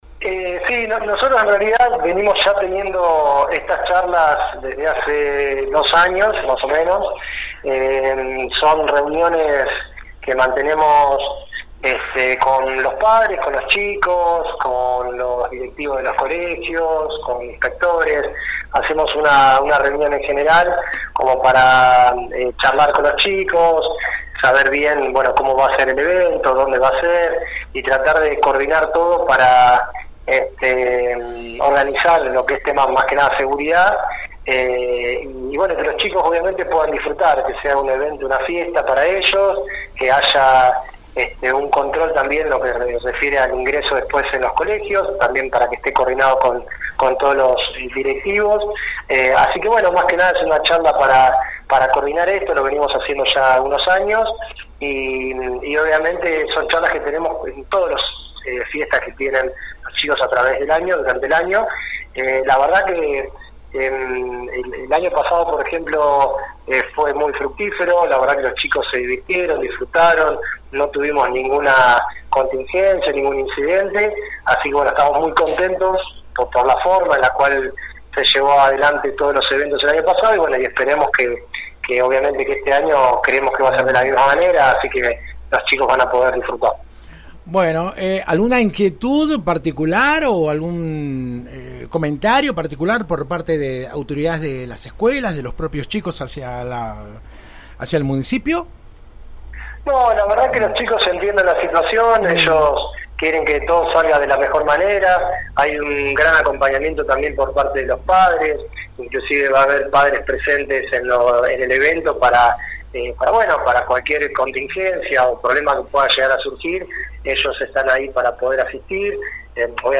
(foto archivo) El subsecretario de Gobierno municipal, Dr. Ricardo Spinelli, habló este jueves con la 91.5 sobre el tradicional festejo de los estudiantes del último año del nivel secundario en el inicio del ciclo lectivo previsto para el próximo lunes 9 de marzo.